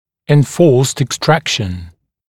[ɪn’fɔːst ɪk’strækʃn] [en-] [ин’фо:ст ик’стрэкшн] [эн-] вынужденное удаление